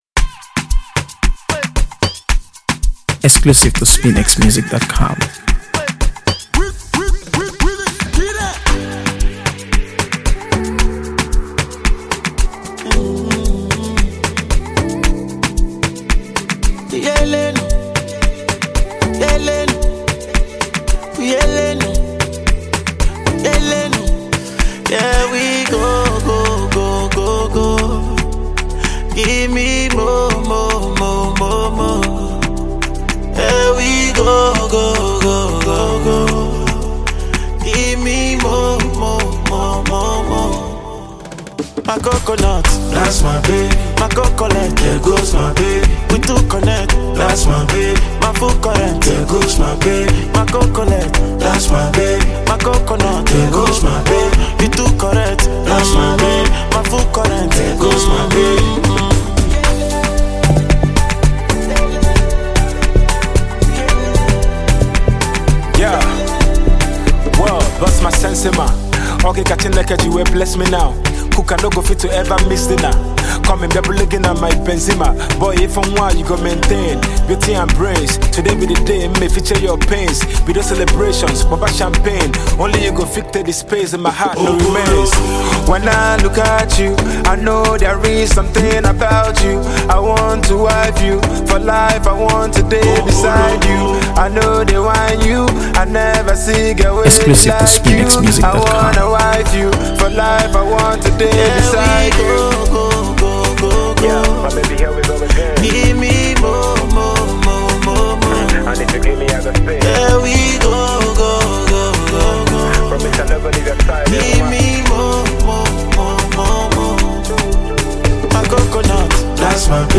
AfroBeats | AfroBeats songs
” which has a catchy and melodious hook